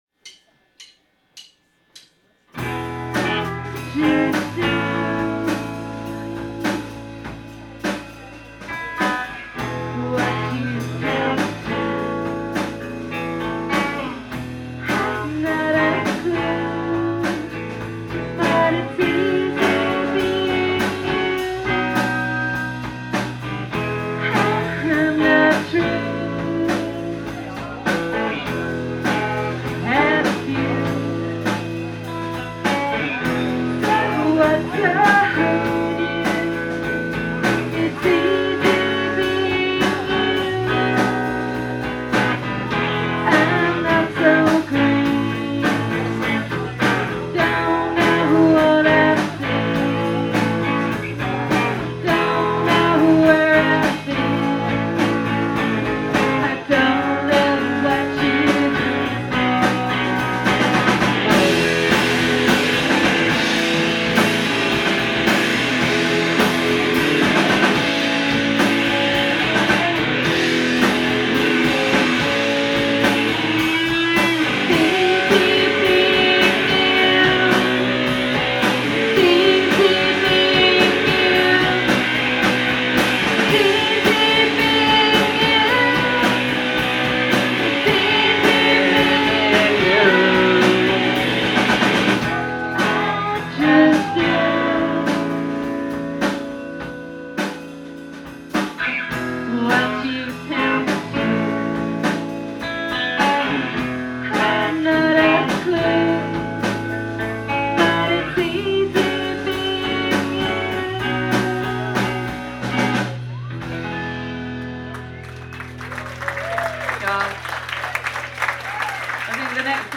steadily intense drumming
alternatingly subdued and fierce voice
repetitive, rolling bass lines
frenetic guitar noise
Live at PA’s Lounge